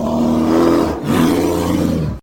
Звуки гризли
Рык серого медведя